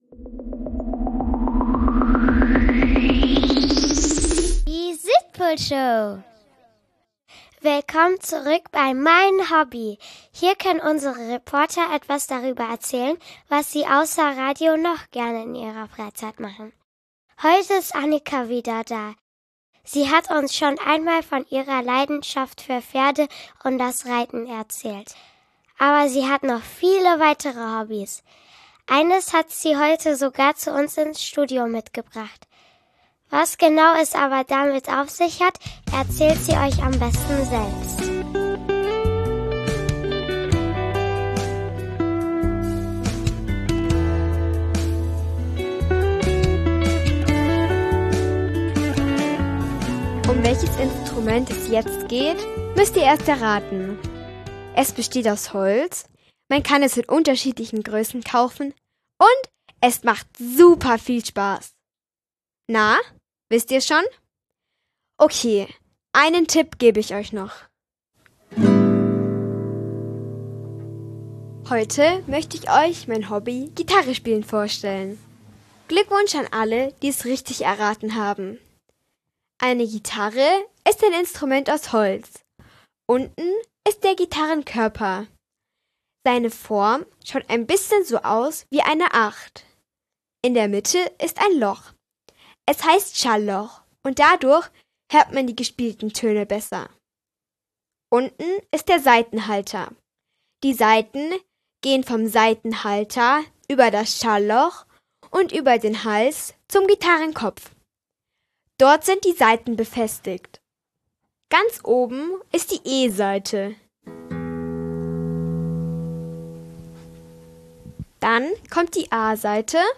Sie zeigt euch die wichtigster Beginnertipps, spielt und singt euch sogar etwas vor.